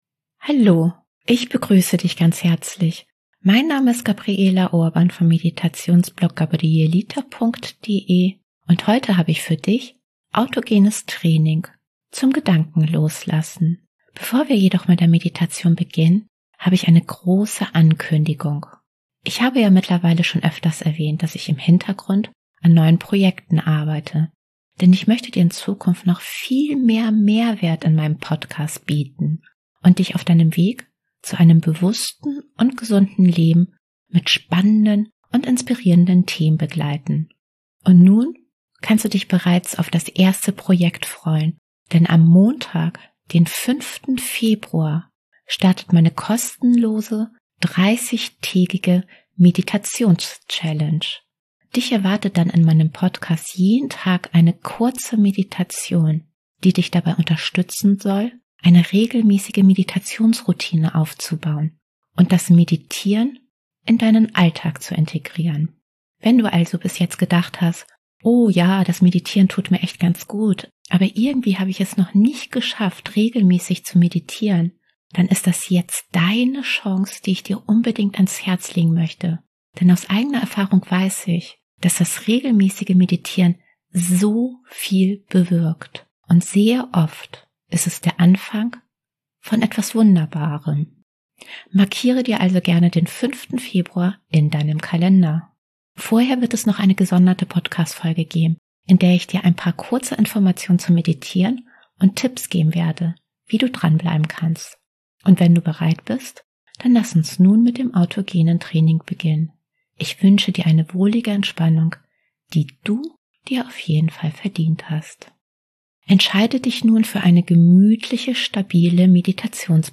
Heute lade ich dich in meinem Podcast zum Autogenes Training Gedanken loslassen ein. Hier kannst du übrigens die Meditation auch OHNE MUSIK runterladen, falls du das bevorzugst.